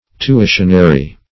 Tuitionary \Tu*i"tion*a*ry\, a.
tuitionary.mp3